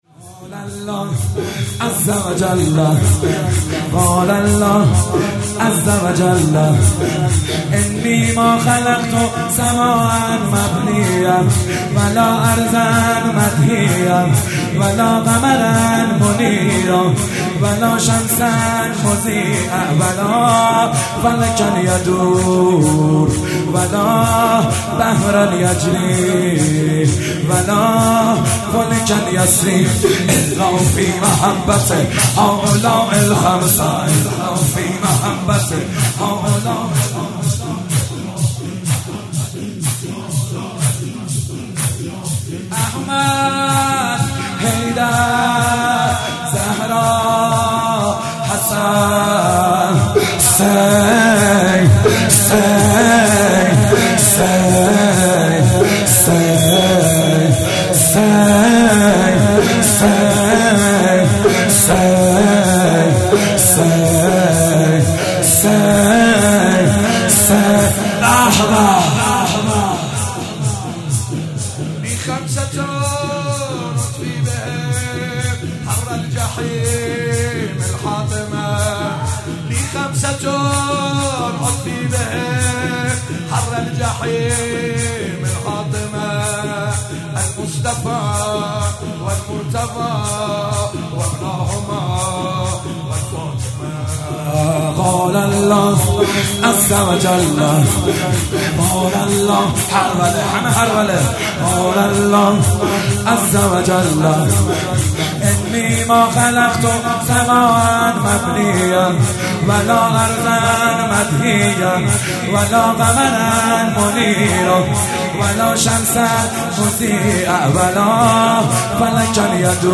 شور عربی جدید